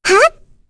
Rephy-Vox_Jump1.wav